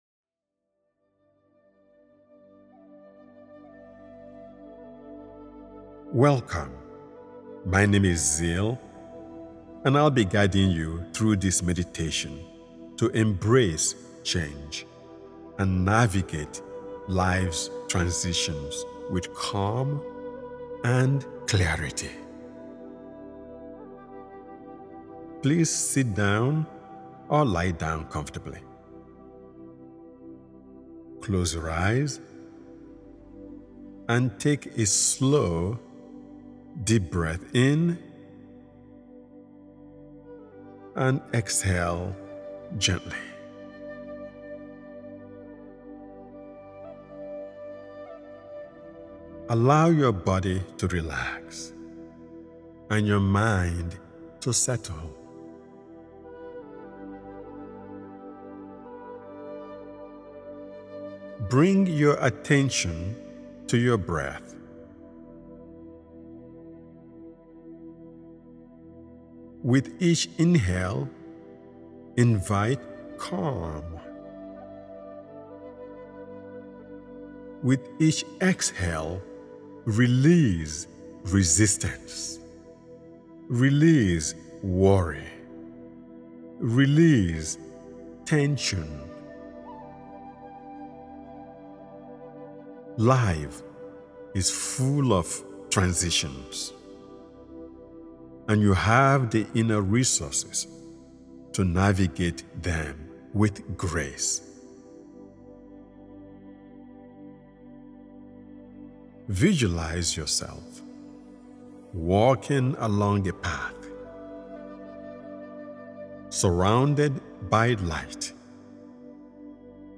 Embrace Change & Life Transitions: A Meditation for Inner Strength and Renewal is a deeply supportive and grounding guided meditation created to help you move gracefully through the shifting seasons of your life.